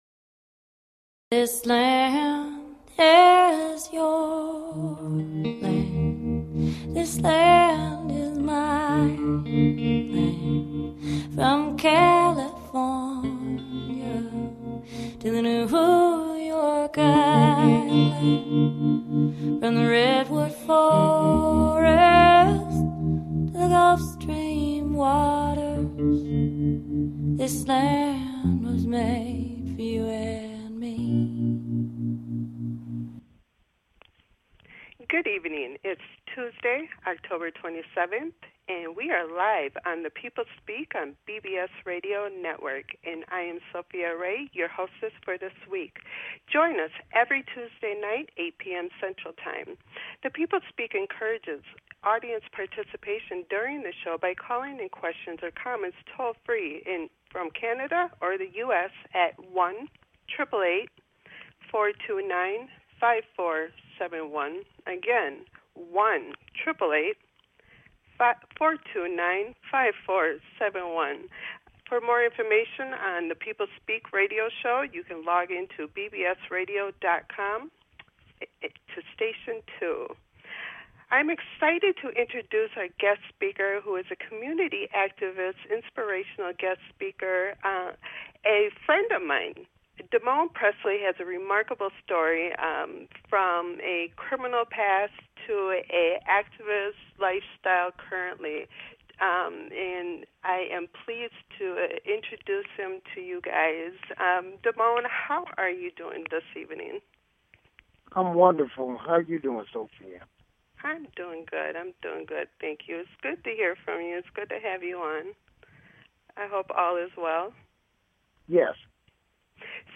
Talk Show Episode, Audio Podcast, The People Speak and Guest